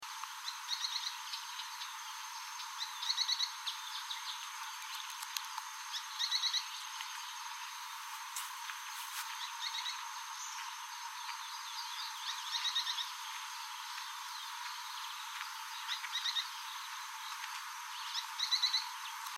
João-teneném (Synallaxis spixi)
Localidade ou área protegida: Reserva Ecológica Costanera Sur (RECS)
Condição: Selvagem
Certeza: Gravado Vocal
pijui_plomizo.mp3